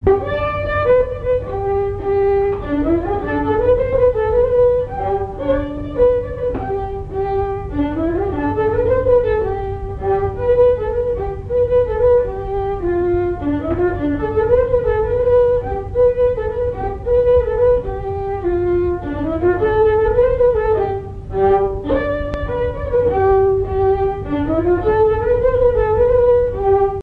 danse : quadrille : galop
Airs à danser aux violons et deux chansons
Pièce musicale inédite